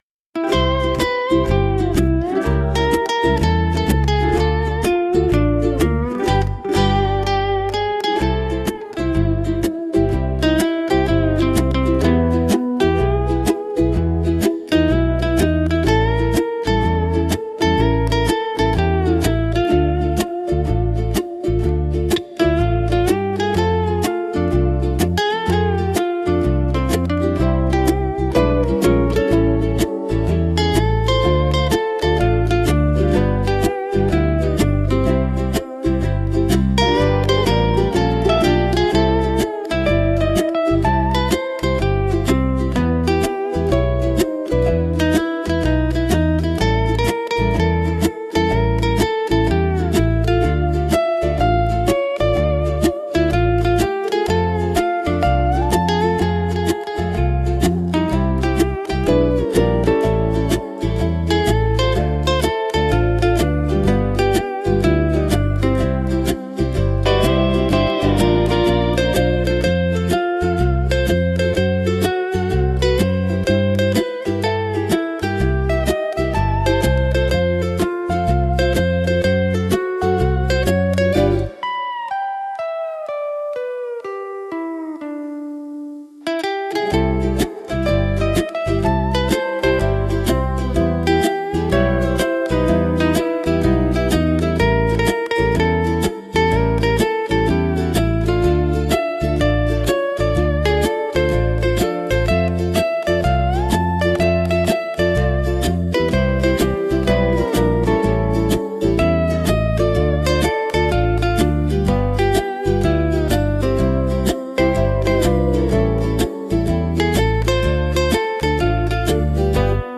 聴く人にゆったりとした安らぎや心地よさを届ける、穏やかで親しみやすいジャンルです。